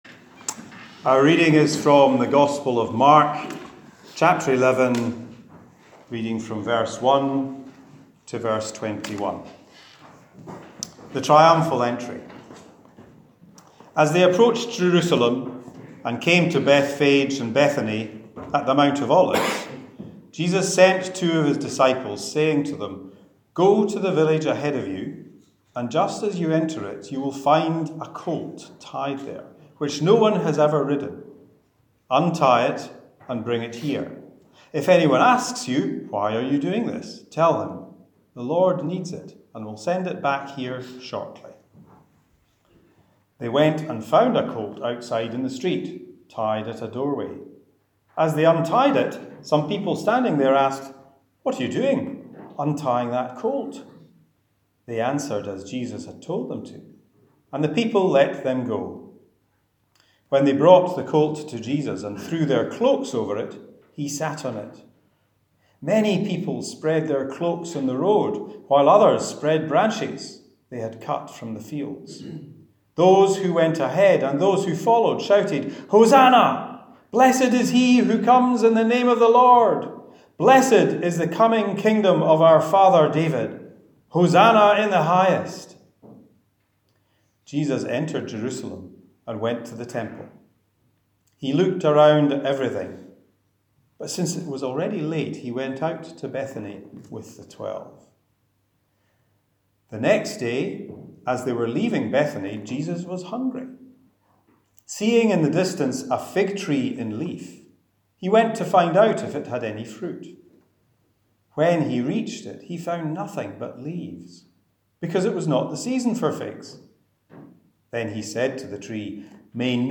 Entering Jerusalem- Sermon 14th April 2019